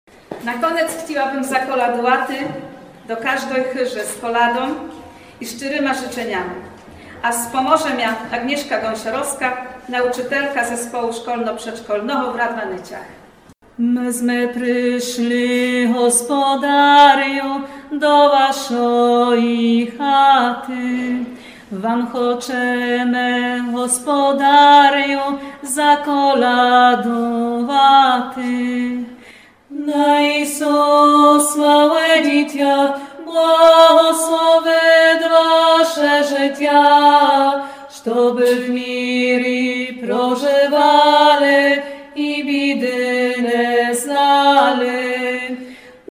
Концерт коляд «Од Cходу до Заходу» з Радваниц - неділя, 14.00 год.
Меджекультуровый концерт «Од Сходу до Захoду» в радваницкій школі прикрасили свойом присутністю і співами молоды і старшы гості з Нижнього Шлеска, медже інчыма з Пшемкова, Шпротавы, Любіна, Ґлоґова, Радваниц і Бучыны.